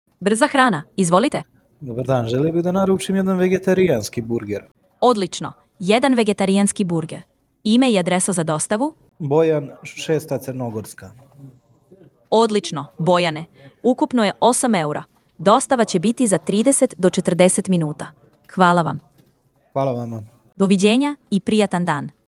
Linia je sistem zasnovan na vještačkoj inteligenciji koji automatski prima i upućuje telefonske pozive, razgovara sa korisnicima i pruža tačne informacije – prirodnim, ljudskim glasom.
Preslušajte primjere AI glasovnog agenta